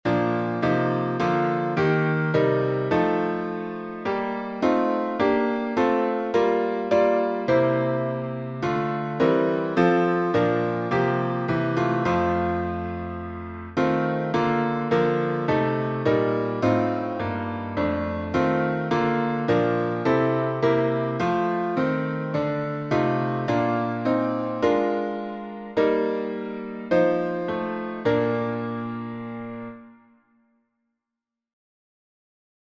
Key signature: B flat major (2 flats) Time signature: 4/4